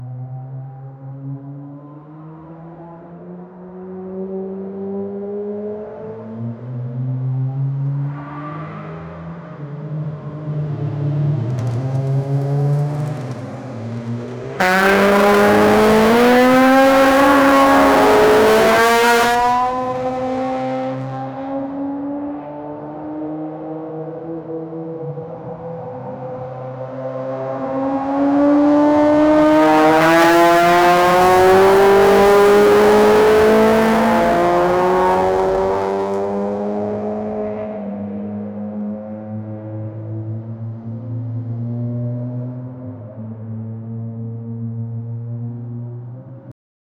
street-car-engine-racing--yj43bozj.wav